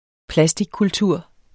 Udtale [ ˈplasdigkulˌtuɐ̯ˀ ]